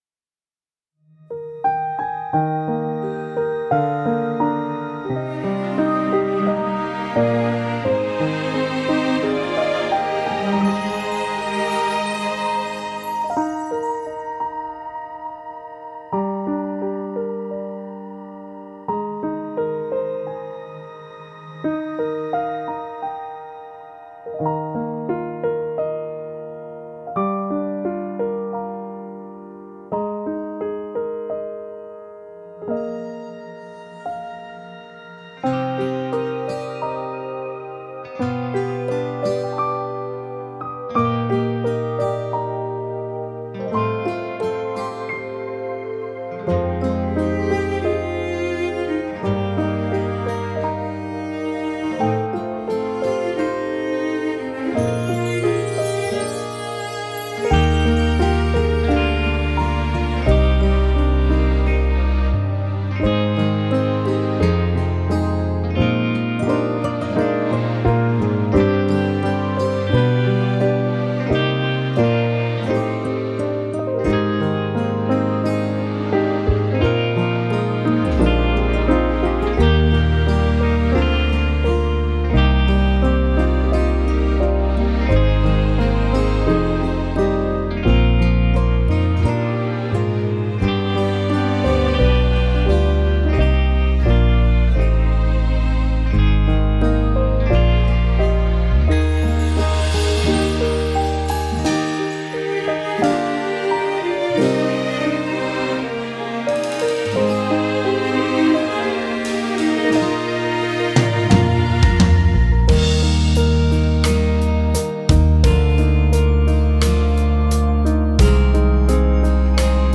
mp3 伴奏音樂